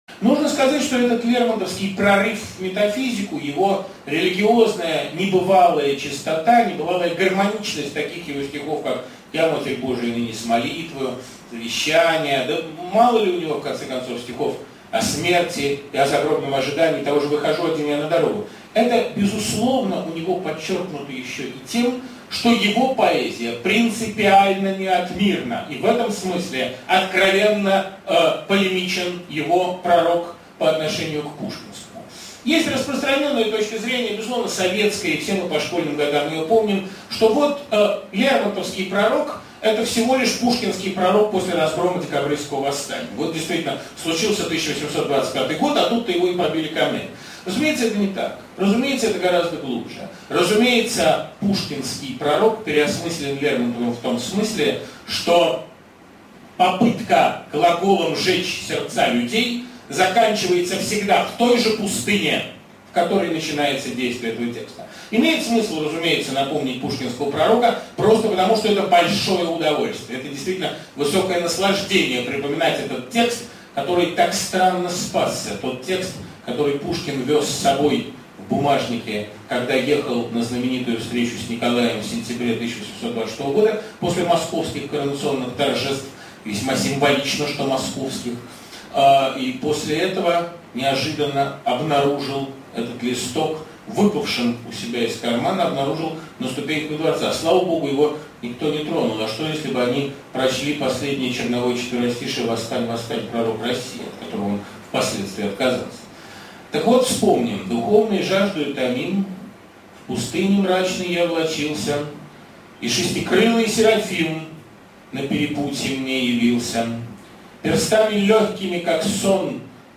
1. «Д.Быков (из лекции) – Два Пророка» /